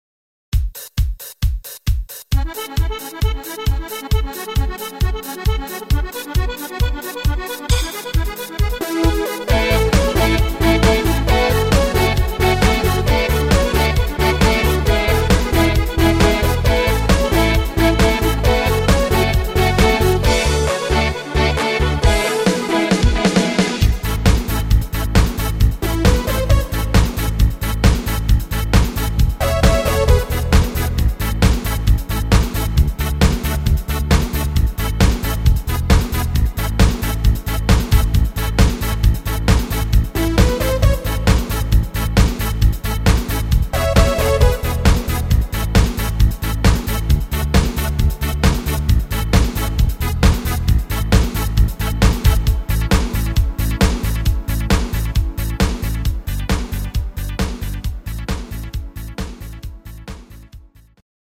Rhythmus  Party Disco
Art  Deutsch, Party Hits, Schlager 90er